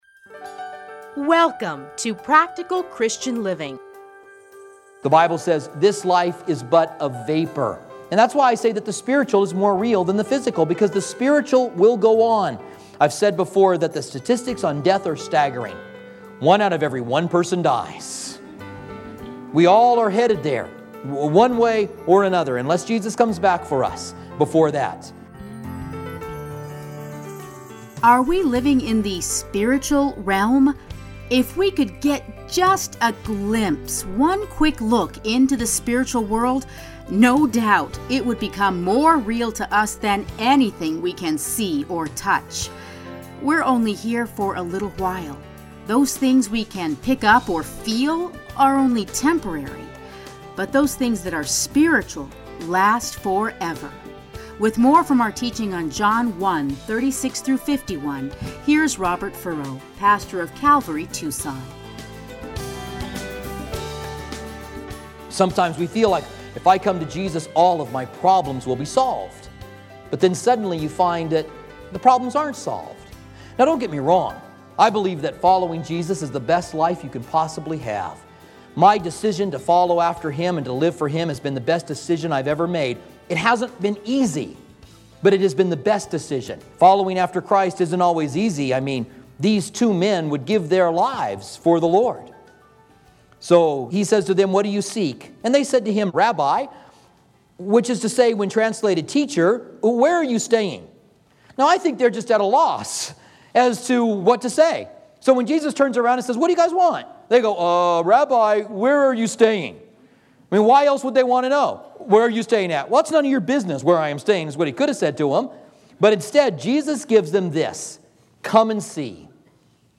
30-minute radio programs titled Practical Christian Living